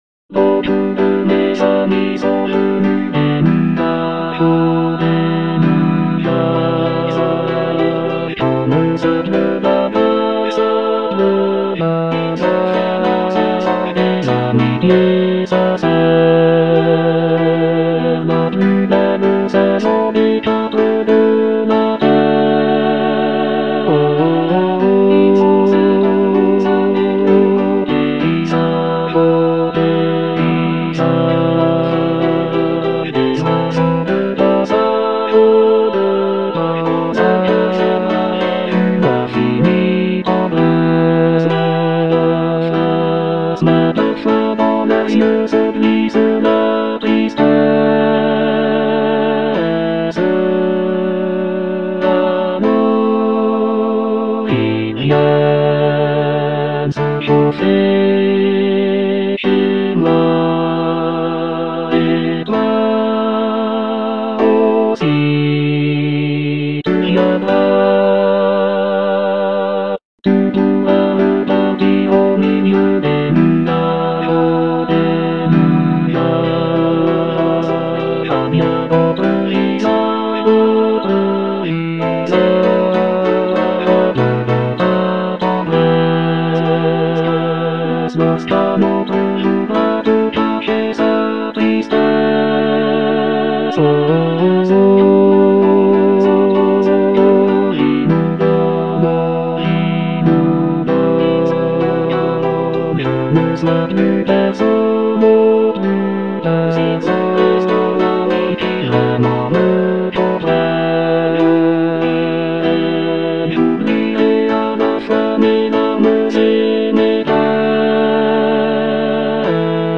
a charming piece for choir
Bass I (Emphasised voice and other voices)